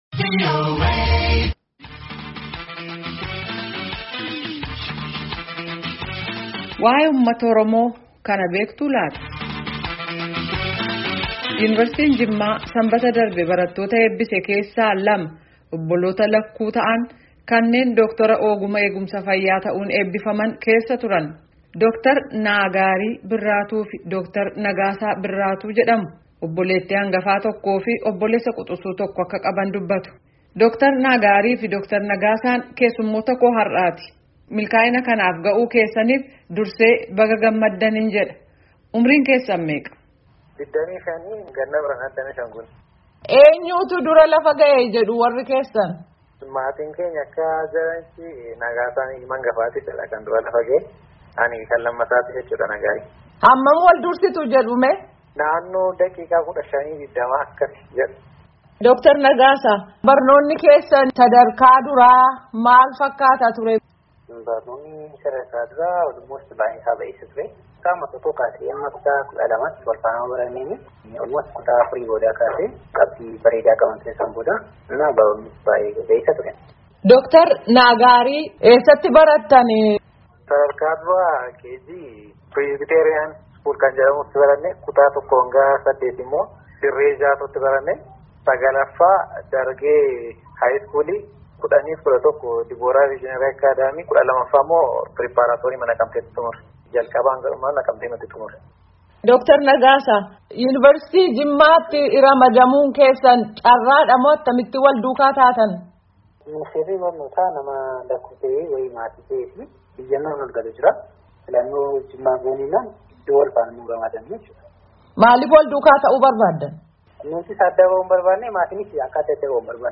Gaaffi fi Deebii Gaggeefamee Caqasaa.